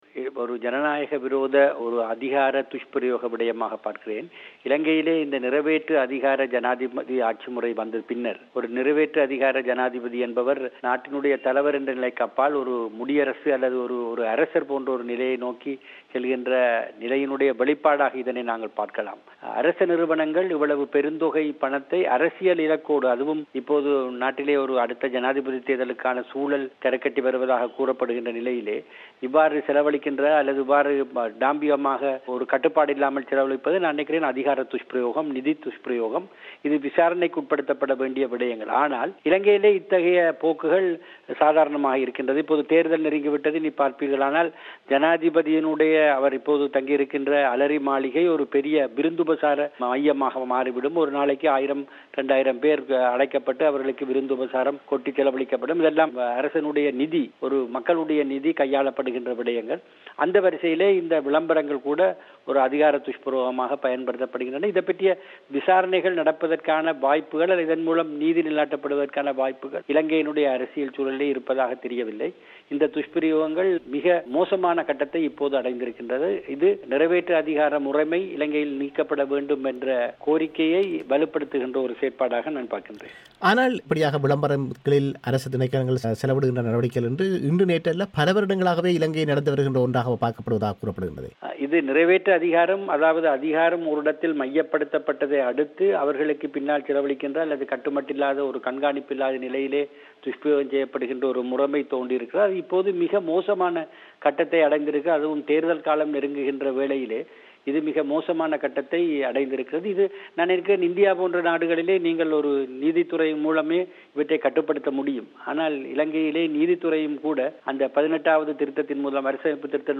இவை குறித்தும், இலங்கையின் ஊடகத்துறையை விளம்பரங்கள் மூலம் அரசாங்கம் கட்டுப்படுத்த விளைவதாக அவர் விபரிப்பது குறித்தும் ஆராயும் அவரது முழுமையான செவ்வியை நேயர்கள் இங்கு கேட்கலாம்.